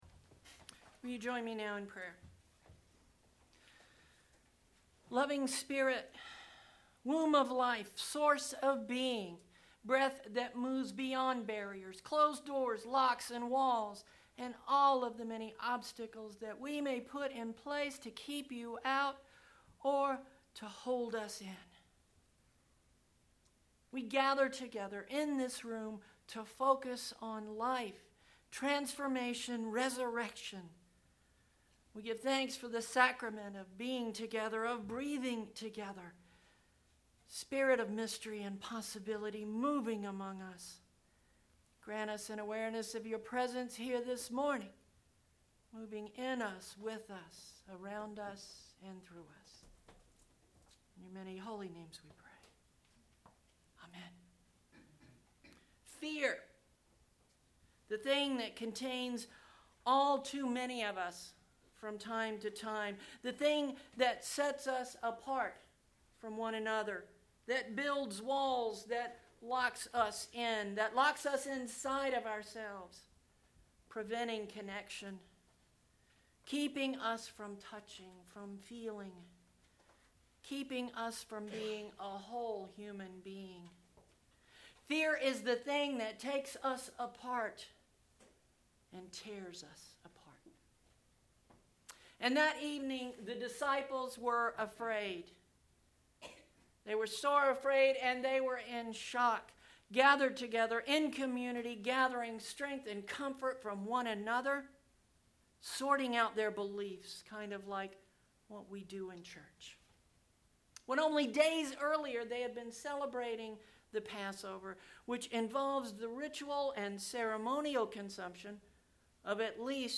Sunday April 28th – Easter 2 Service |